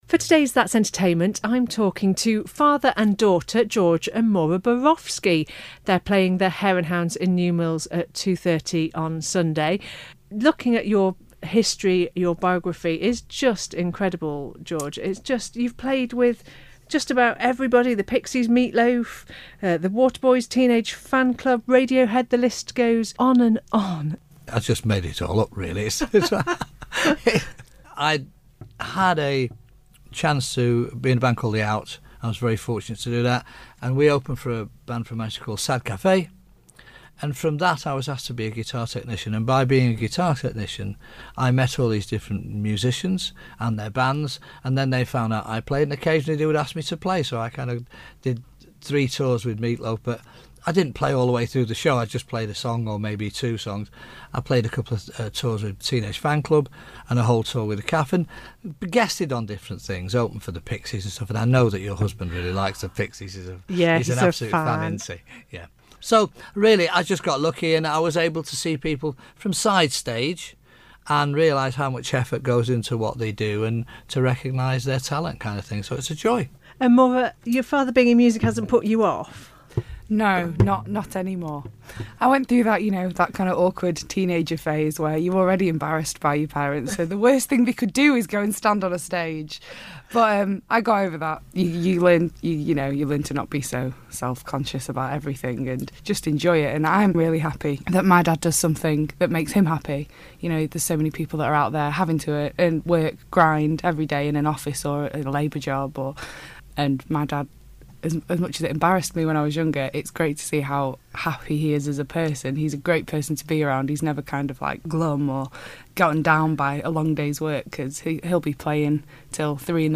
singer and guitarist
came into the studio to talk to us and play a track live.